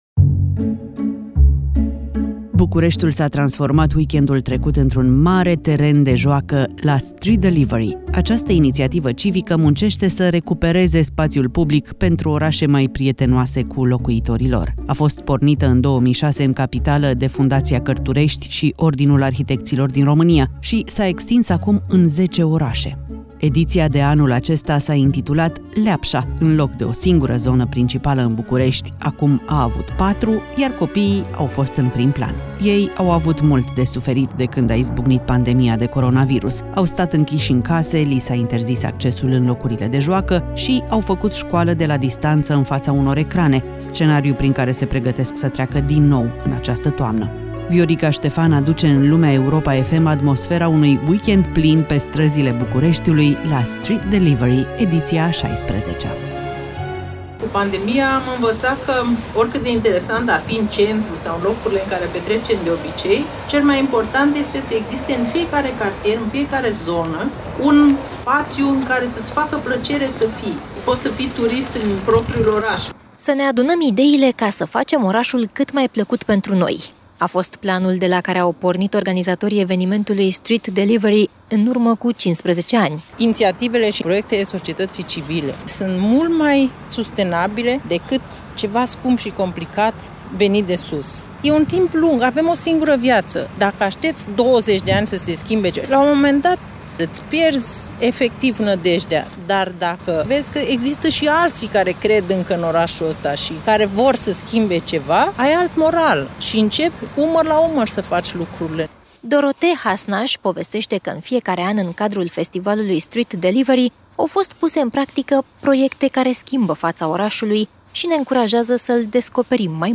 atmosfera unui weekend plin pe străzile Bucureștiului, la Street Delivery , edițea a 16-a.